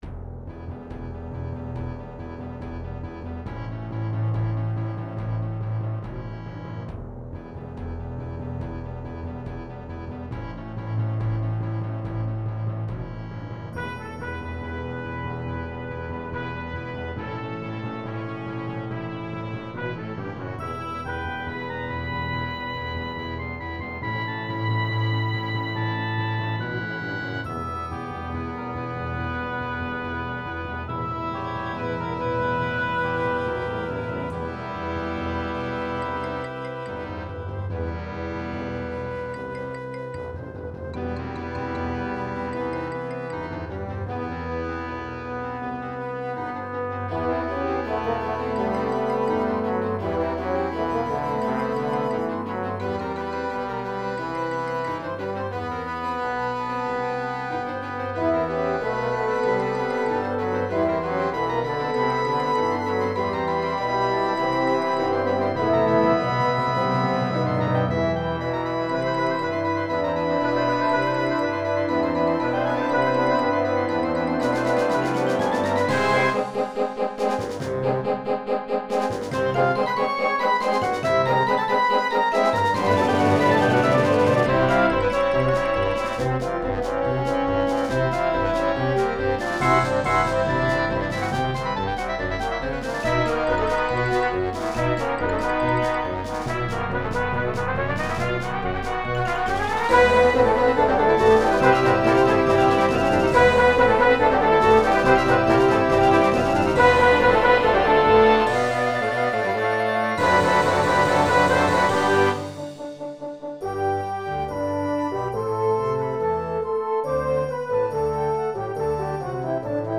I happened to be doing some reading about the Vikings while listening to it and I have to say - it fit pretty well!